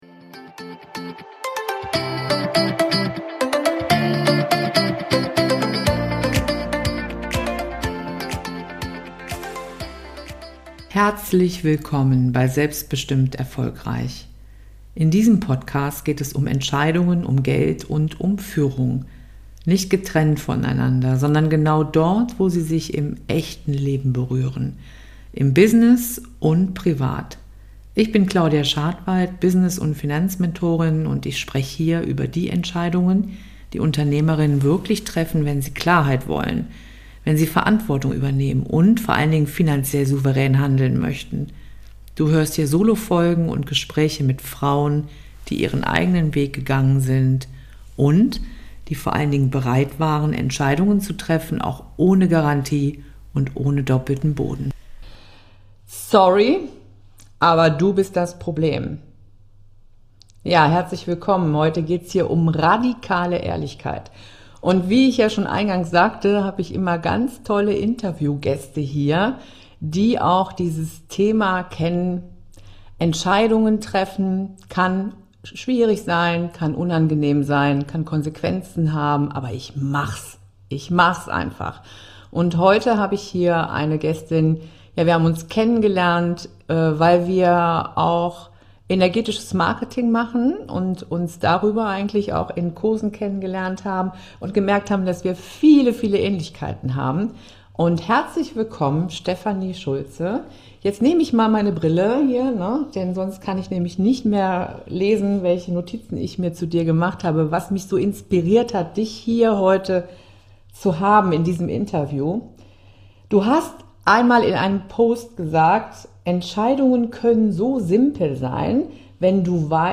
Zwei Unternehmerinnen sprechen über Zweifel, Umfeldstimmen, finanzielle Unsicherheiten und über diesen Moment, in dem du merkst: So geht es nicht weiter.